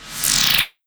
gestureFailed.wav